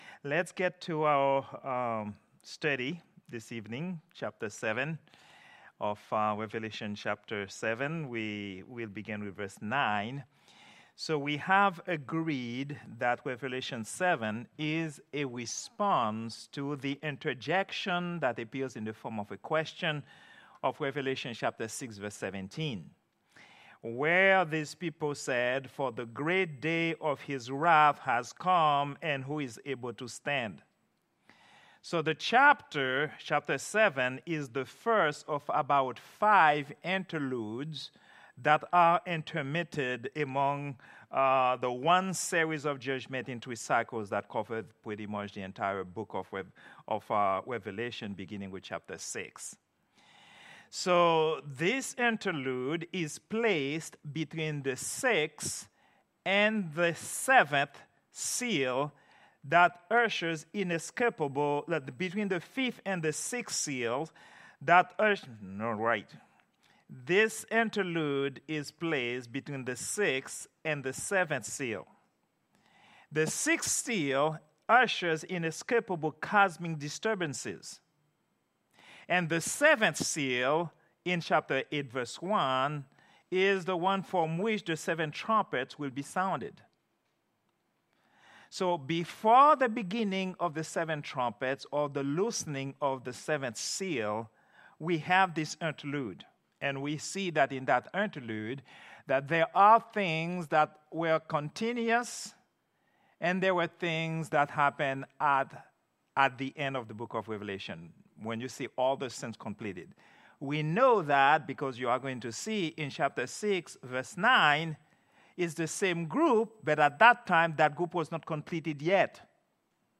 Prayer_Meeting_08_14_2024.mp3